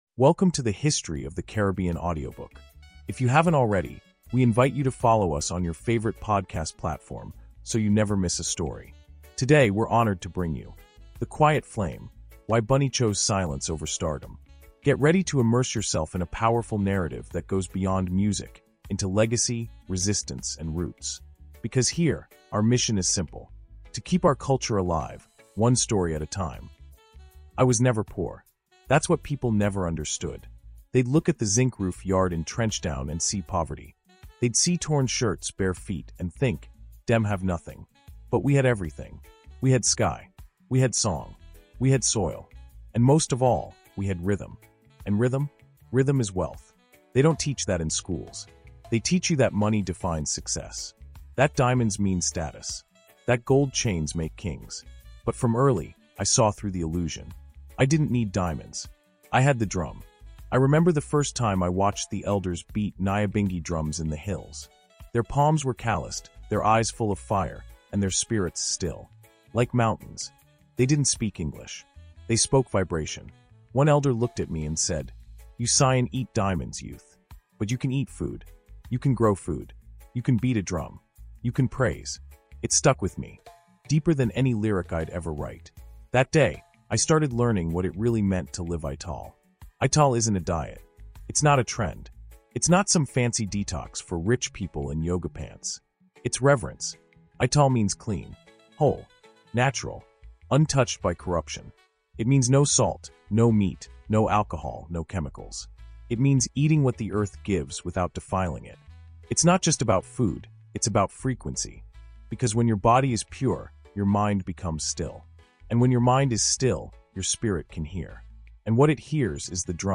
Drums Over Diamonds is not a dietary lecture.